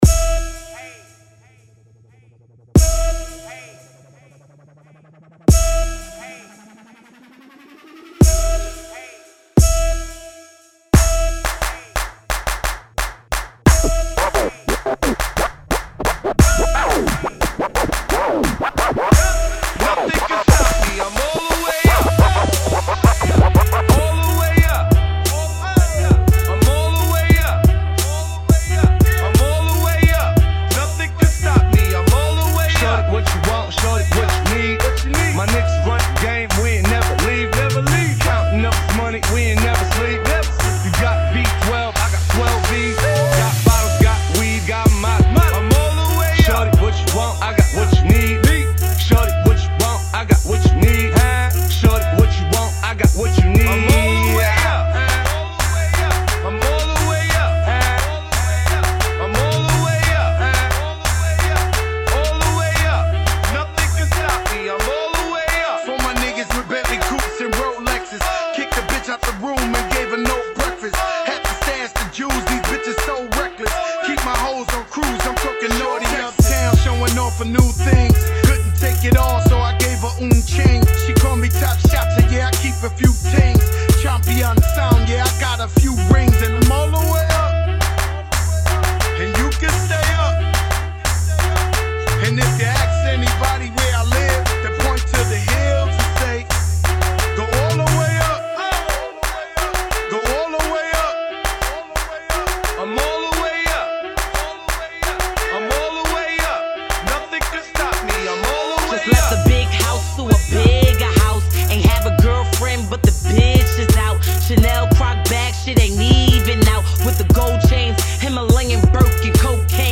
88 Bpm